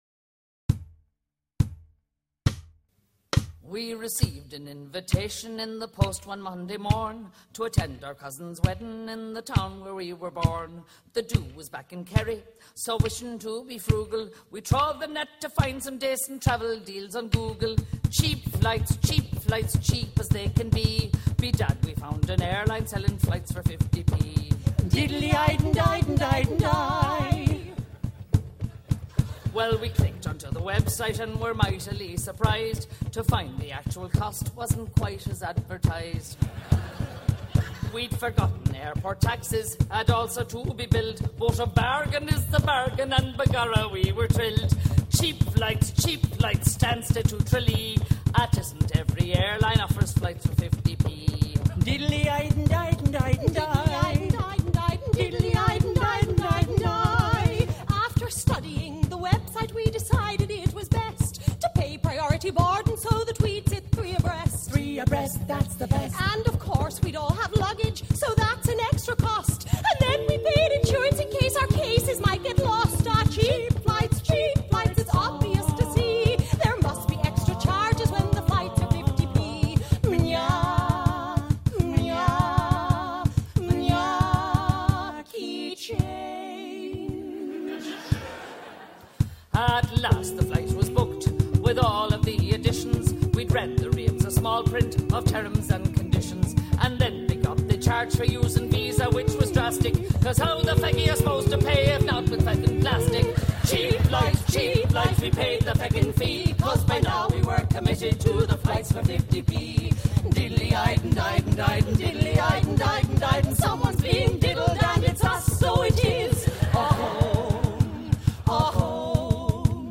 Backing Track Cheap Flights WITH TRACK